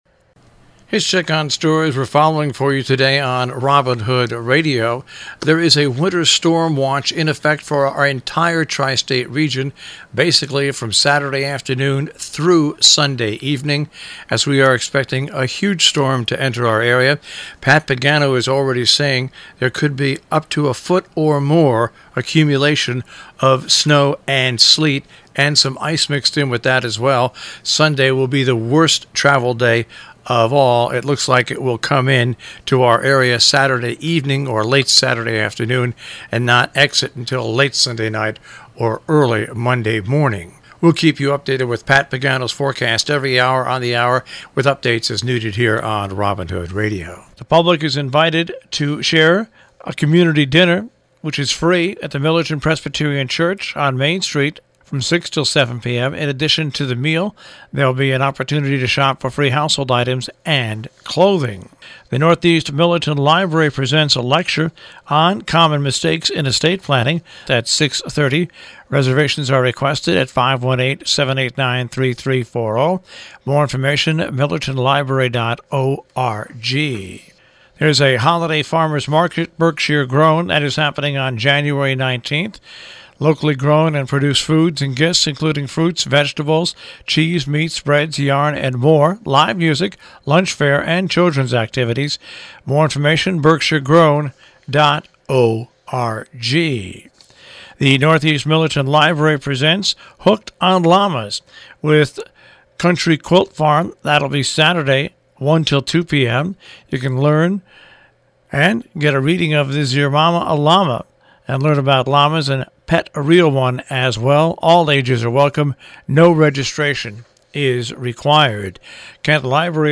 news and events in the Tri-State Region on The Breakfast Club on Robin Hood Radio